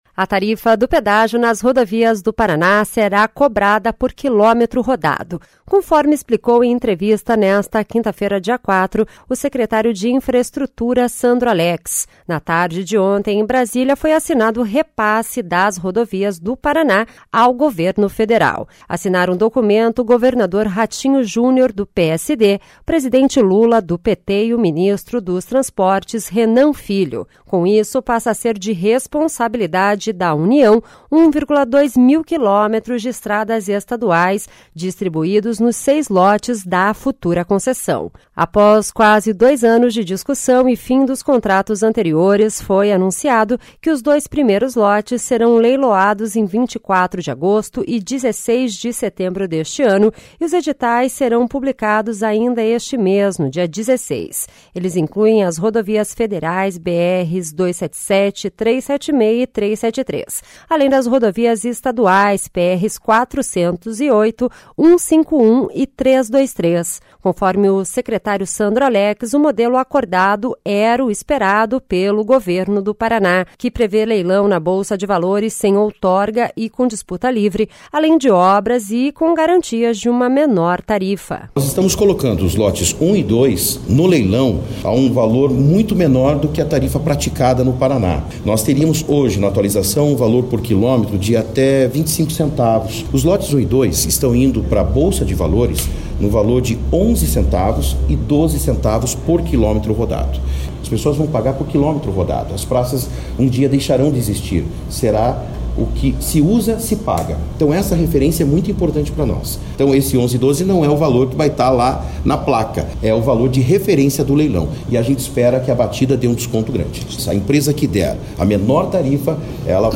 A tarifa do pedágio nas rodovias do Paraná será por quilômetro rodado, conforme explicou em entrevista nesta quinta-feira (04), o secretário de Infraestrutura, Sandro Alex.